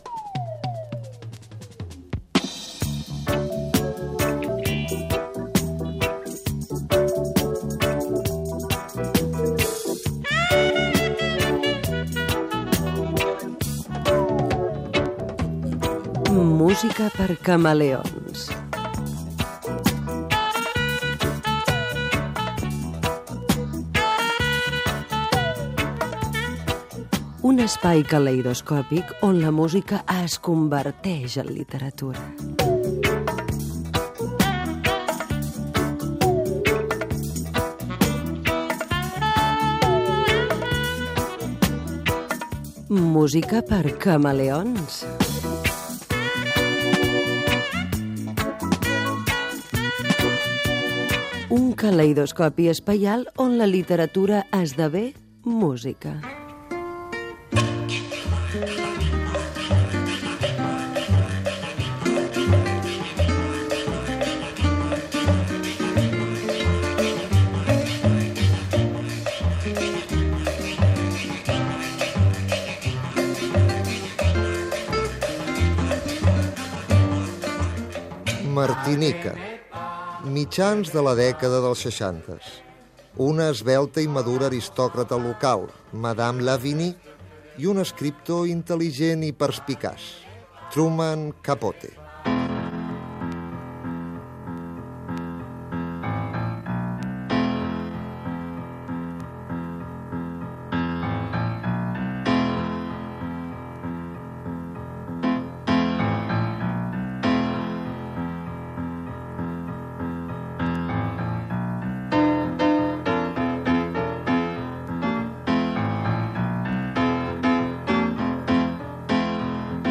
Careta d'entrada i espai dedicat a la música de la Martinica i a l'escriptor Truman Capote.
Musical